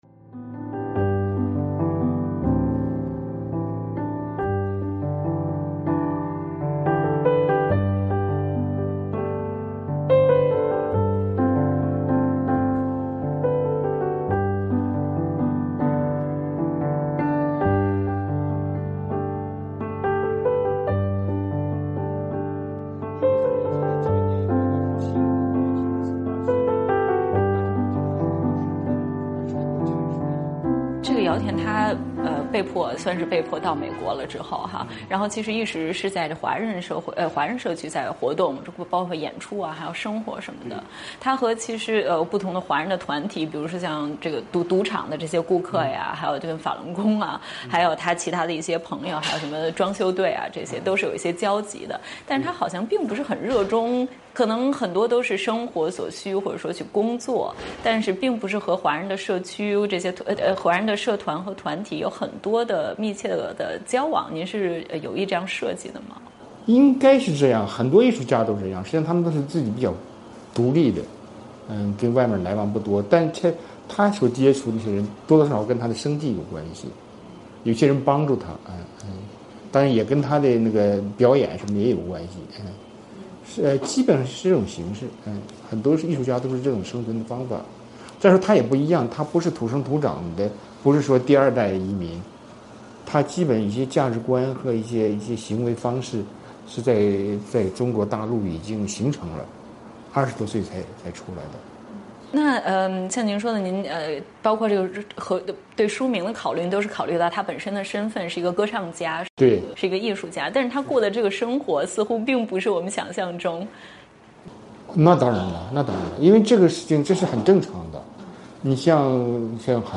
美国之音专访: 华裔作家哈金谈长篇小说《放歌》，分析中国社会及文学现状